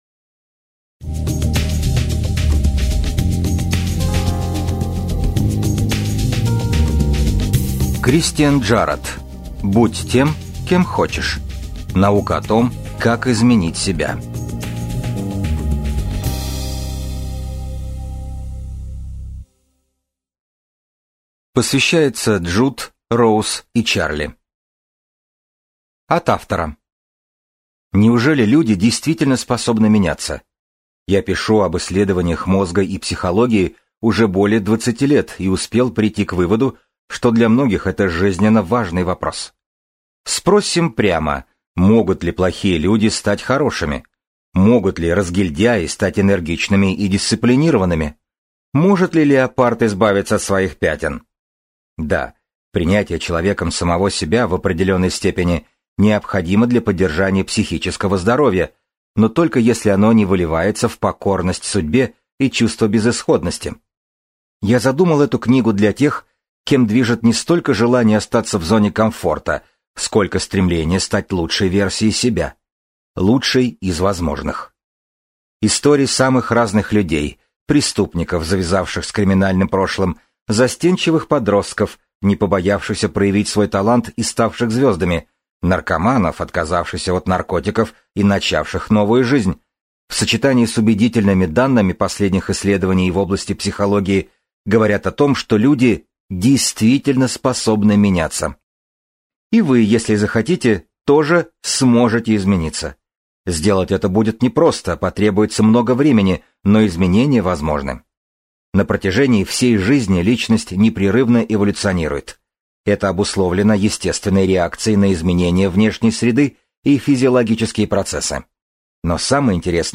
Аудиокнига Будь тем, кем хочешь: Наука о том, как изменить себя | Библиотека аудиокниг